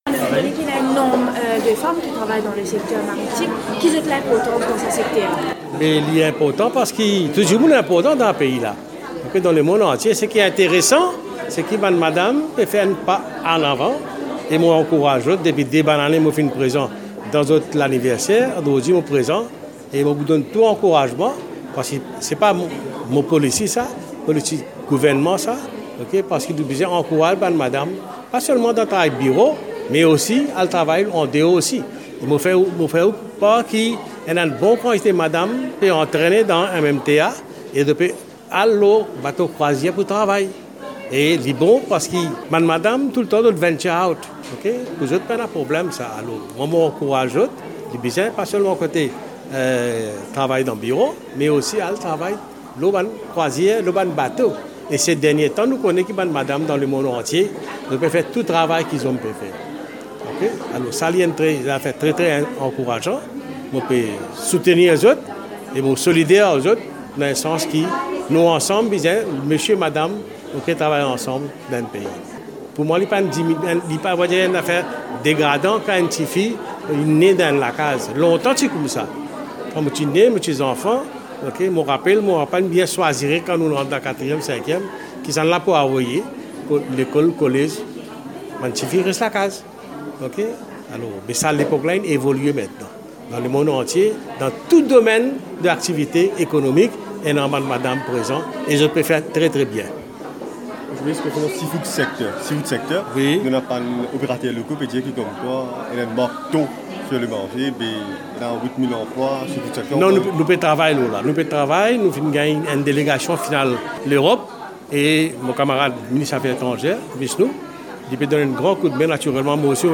Le ministre de la Pêche et de l’économie océanique intervenait, ce jeudi 21 septembre, lors d’un atelier de travail, à Mer-Rouge, à l’initiative de l’association Women Managers in the Maritime Sector of Eastern and South Africa. D’ajouter qu’un bon nombre de femmes travaillent dans le secteur maritime et que plusieurs suivent actuellement des formations pour prendre de l’emploi sur les bateaux de croisières.